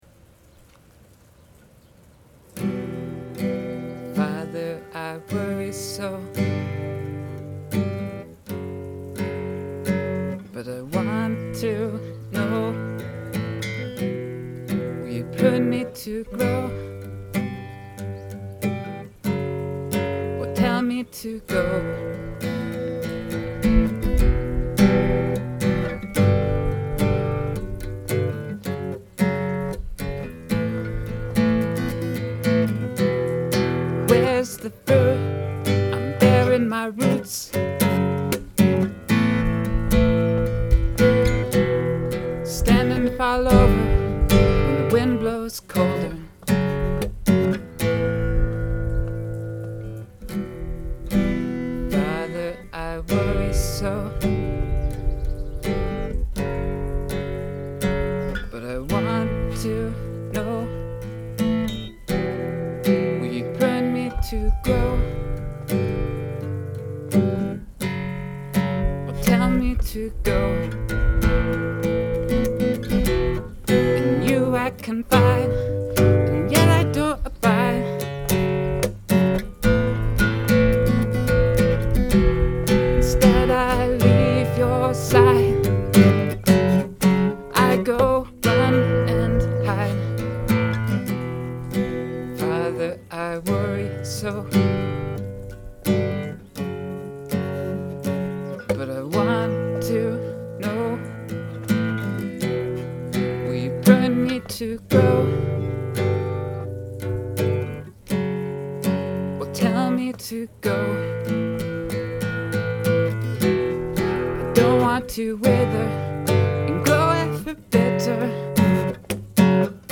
2007 Key: E minor Lyrics Father